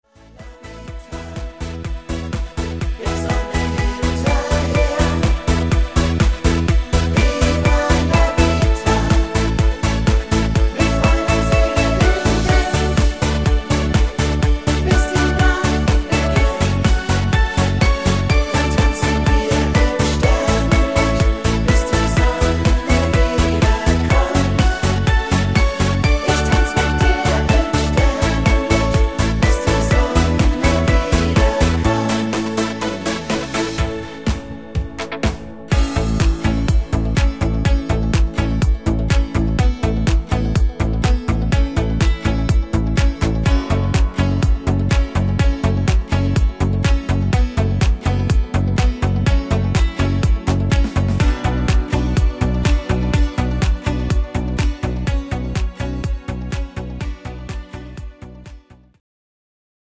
Rhythmus  Discofox
Art  Österreich, Party Hits, Popschlager, Schlager 2020er